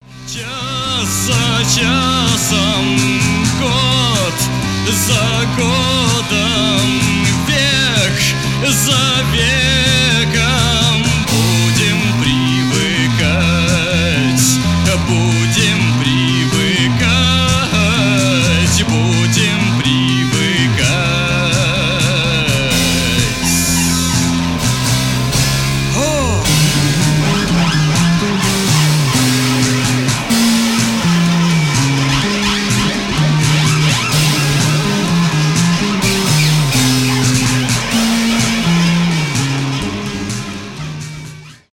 garage rock
пост-панк
психоделический рок